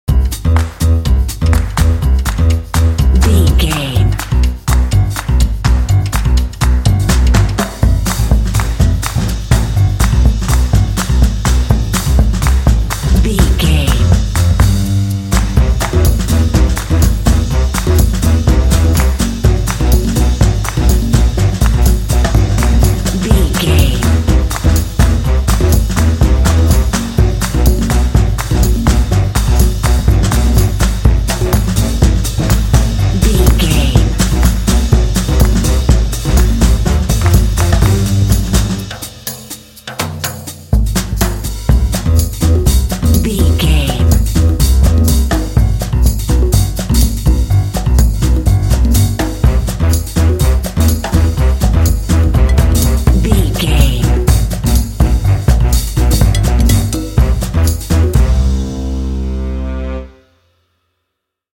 Ionian/Major
bouncy
energetic
happy
groovy
piano
drums
brass
percussion
jazz
bossa